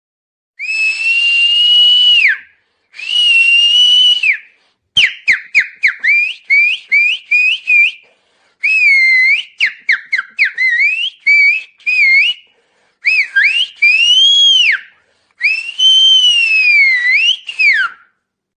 صحبت های مهم وینفرد شفر در کنفرانس خبری قبل از دربی